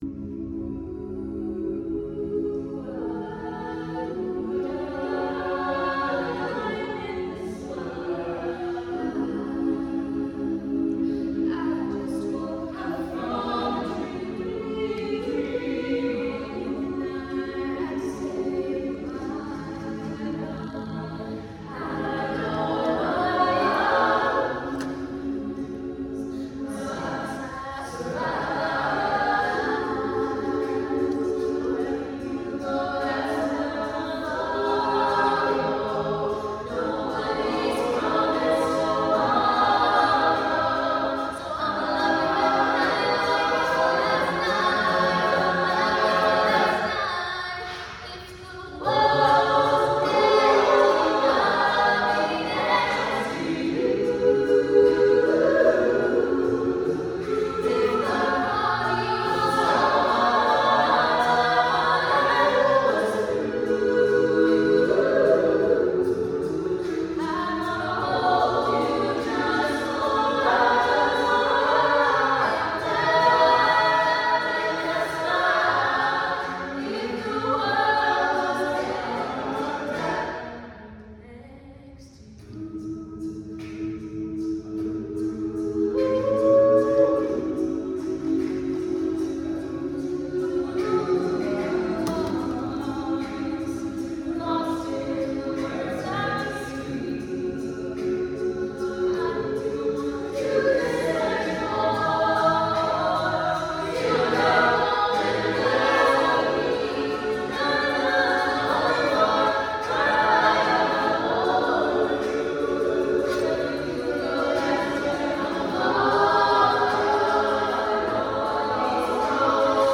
Behind every door and on every quad are horror movies, scavenger hunts, parties for everything and nothing, billiards games, tap dance practices, a cappella rehearsals, improv sketches, pumpkin smashing.
08:21 Extreme Measures Acapella at St. Mark's
singing_24hrsatUConn_8pm.mp3